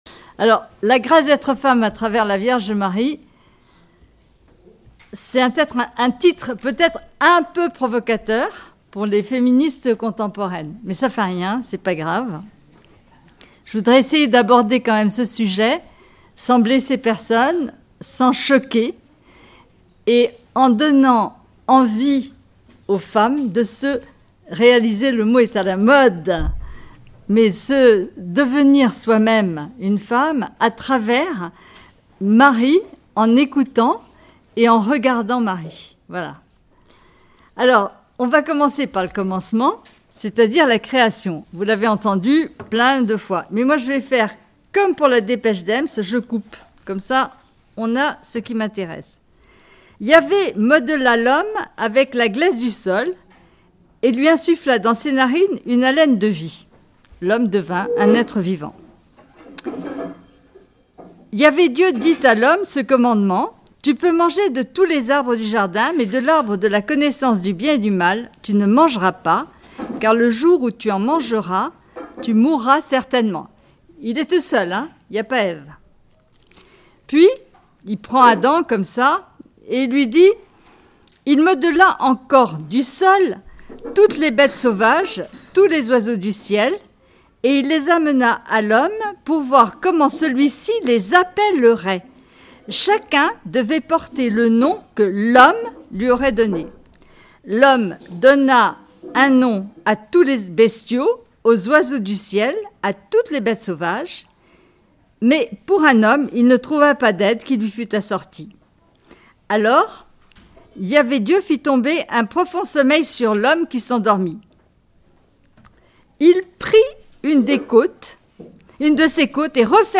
ND du Laus Festival marial août 2023